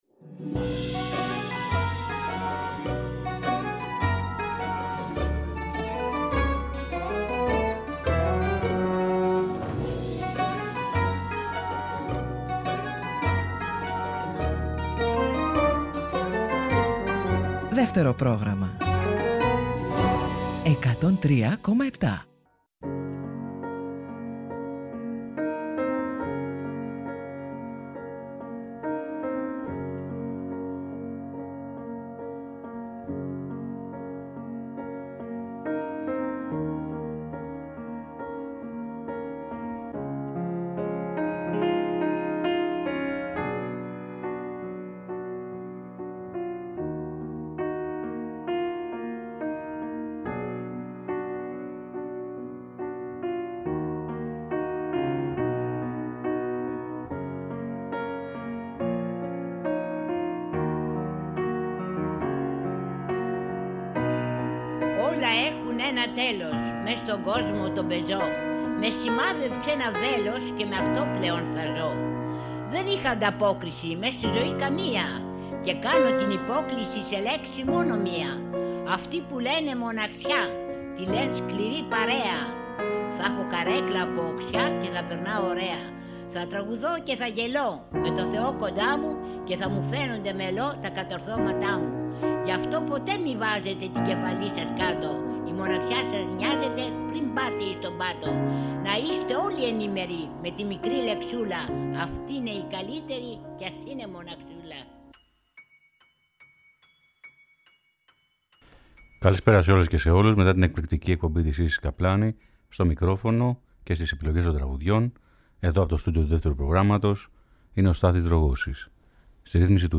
Τραγούδια αιθέρια , ανελαστικά ρομαντικά και ουράνια